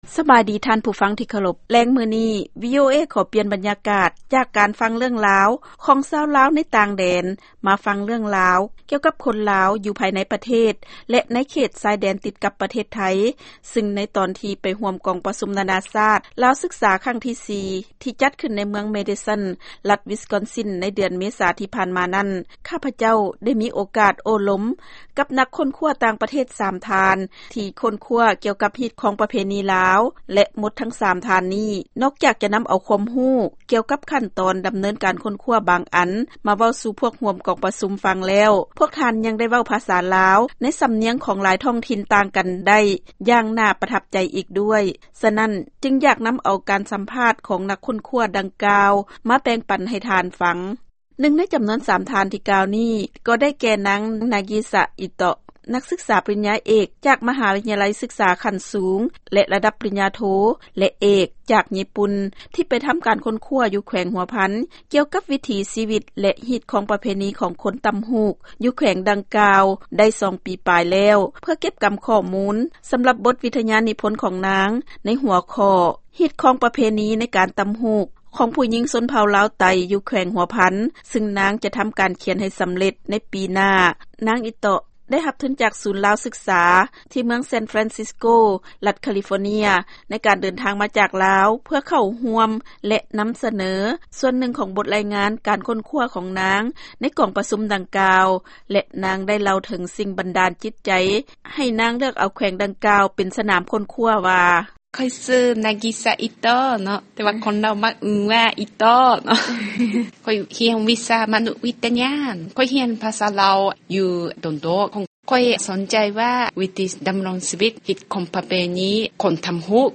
ຟັງລາຍງານການສໍາພາດ ກັບນັກຄົ້ນຄວ້າຕ່າງປະເທດ ກ່ຽວກັບລາວ